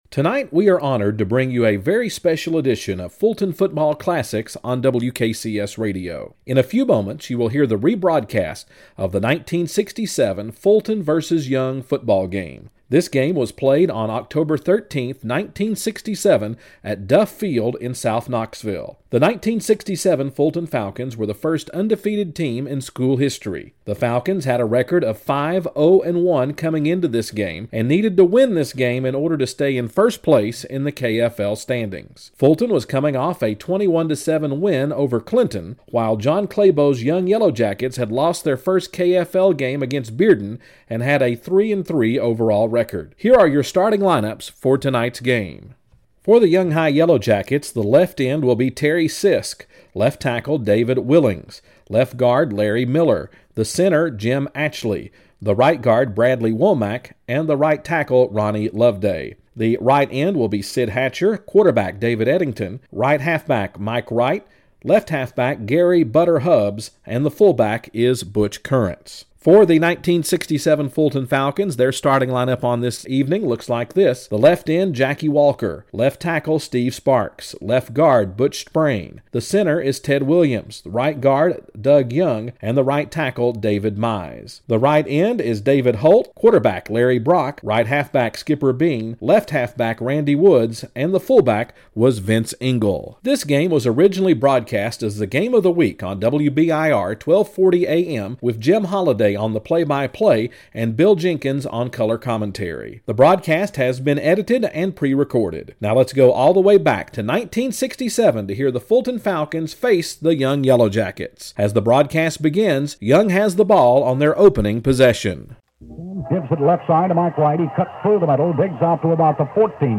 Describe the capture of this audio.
This is the edited broadcast of the WBIR 1240 AM Game of the Week from October 13, 1967 as the undefeated Fulton Falcons traveled to Duff Field to face the Young High Yellow Jackets. This broadcast was recorded directly over the air by the parent of a Fulton player.